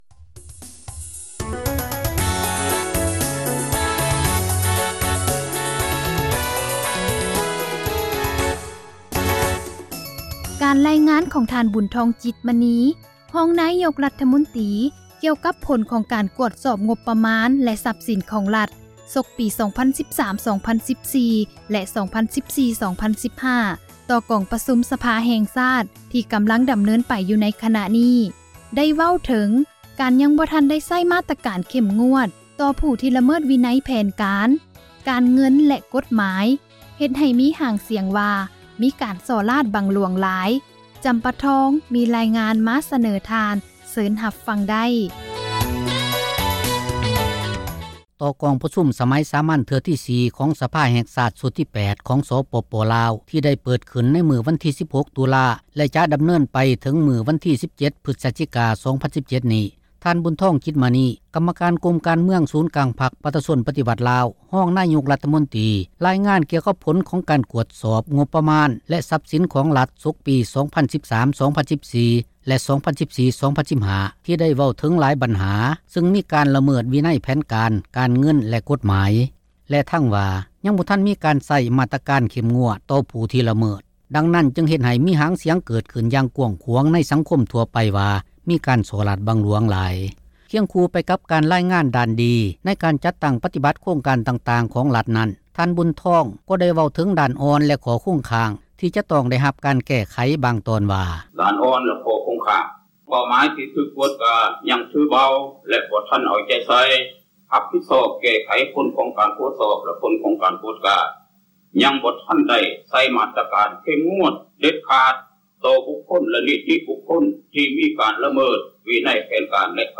ສິ່ງທີ່ ທ່ານ ບຸນທອງ ຈິດມະນີ ກໍາມະການກົມການເມືອງ ສູນກາງພັກປະຊາຊົນປະຕິວັດລາວ, ຮອງນາຍົກຣັຖມົນຕຣີ ເວົ້າມານັ້ນ ເປັນ ສ່ວນໜ້ອຍ ນຶ່ງໃນບົດຣາຍງານ ຂອງທ່ານ ຕໍ່ກອງປະຊຸມ ສມັຍສາມັນເທື່ອທີ 4 ຂອງສະພາແຫ່ງຊາດ ຊຸດທີ 8 ທີ່ກໍາລັງດໍາເນີນໄປຢູ່ ໃນຂະນະນີ້.